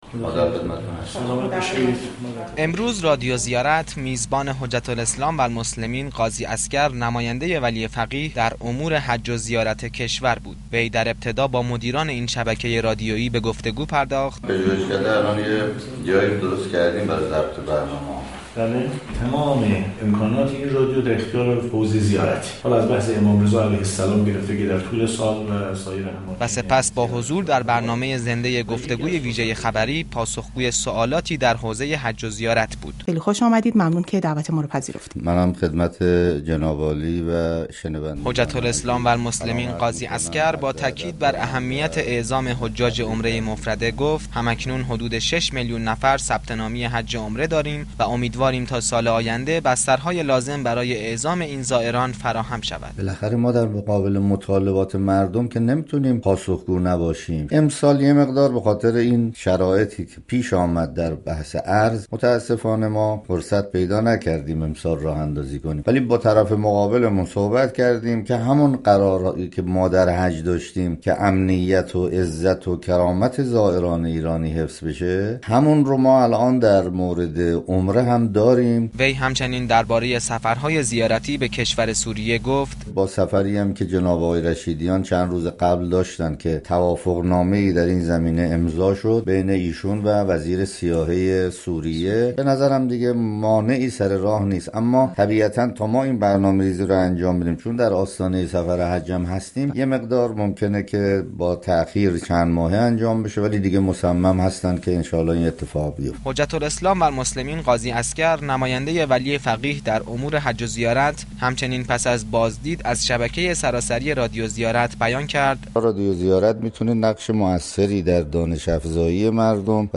نماینده ولی فقیه در امور حج و زیارت ظهر امروز در گفتگوی ویژه خبری رادیو زیارت از راه اندازی عمره مفرده پس از حج تمتع 98 خبر داد.